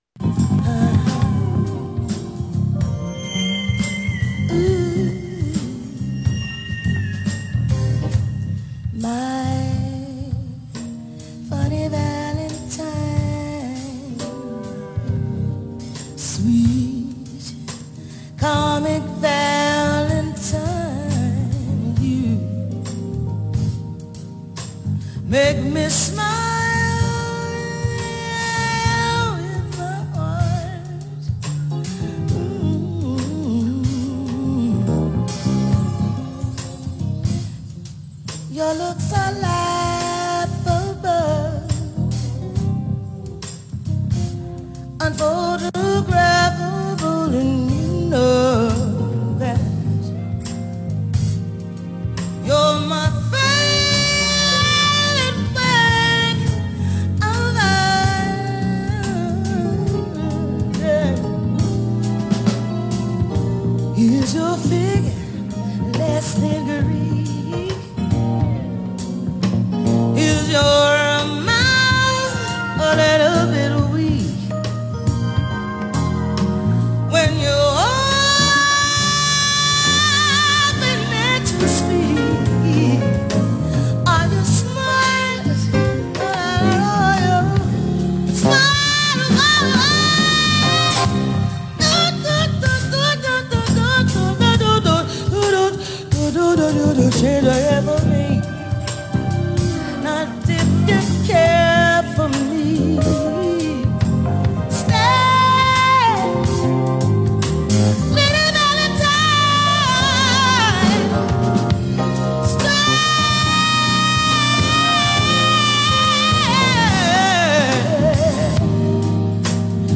E minor